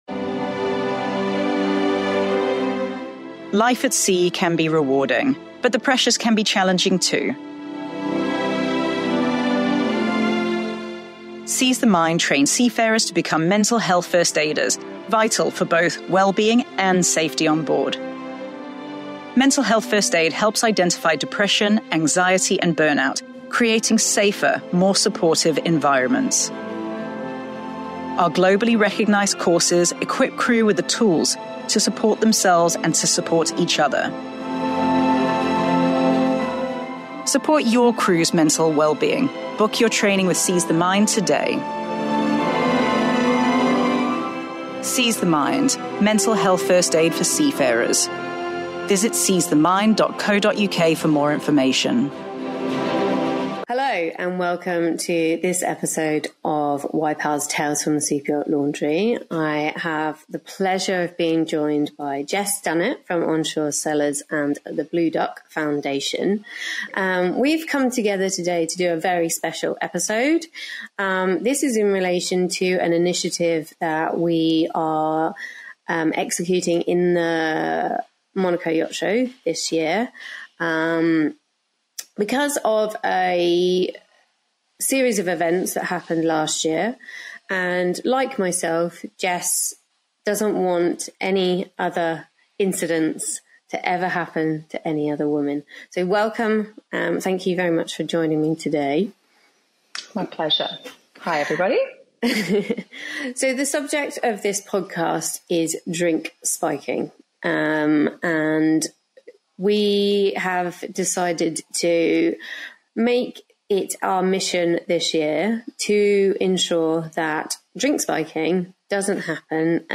This eye-opening conversation is essential for crew, guests, and industry professionals who want to protect themselves and others.